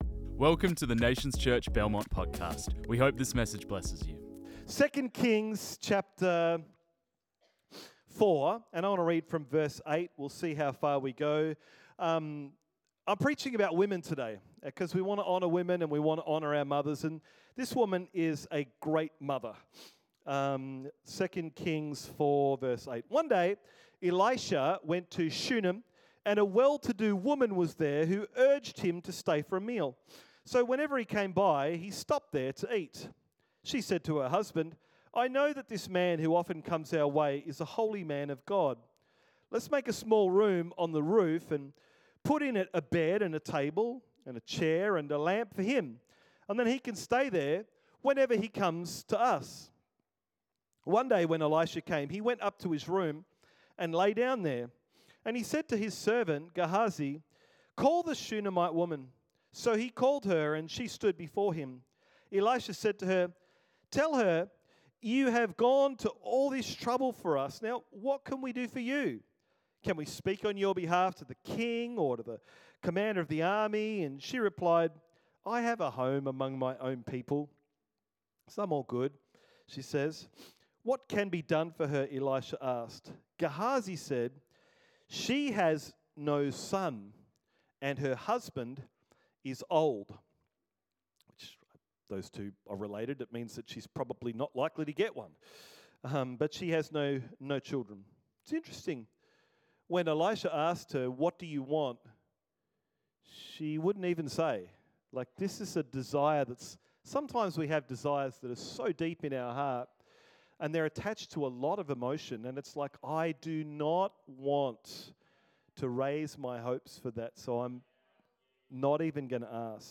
This message was preached on 11 May 2025.